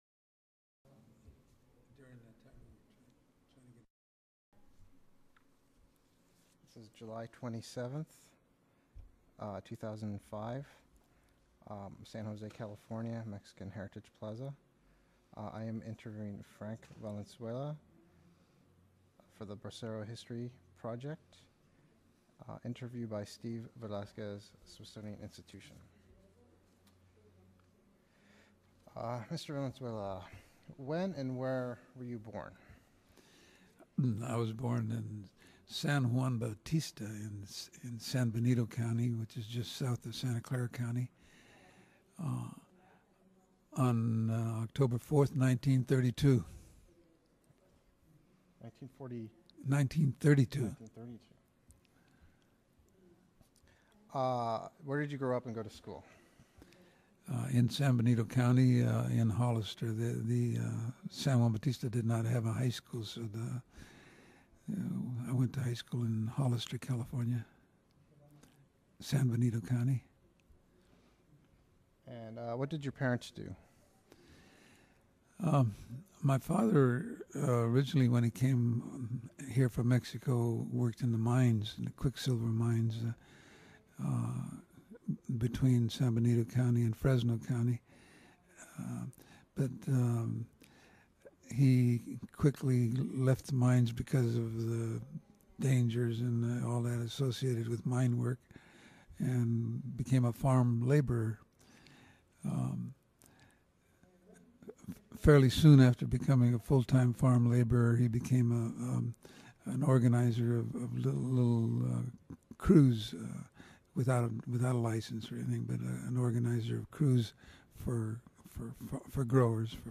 Original Format Mini disc